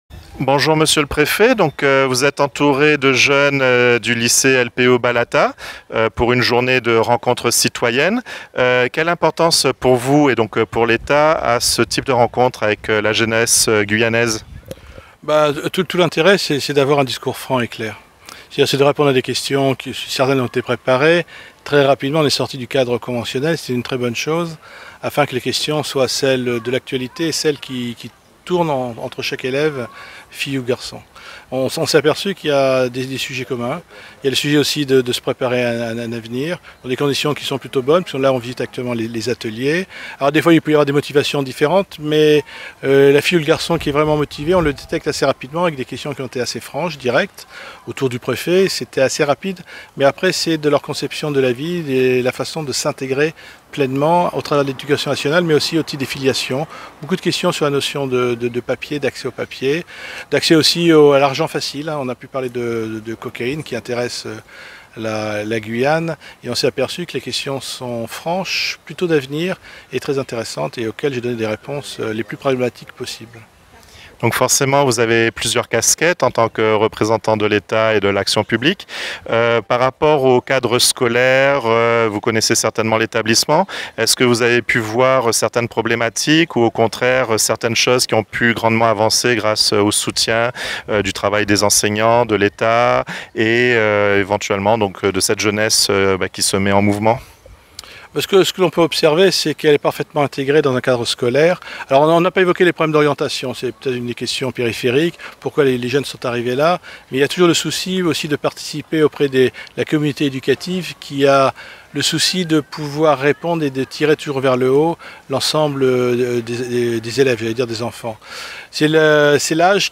Thierry Queffelec, préfet de Guyane à la rencontre des jeunes du lycée LPO de Balata, reportage.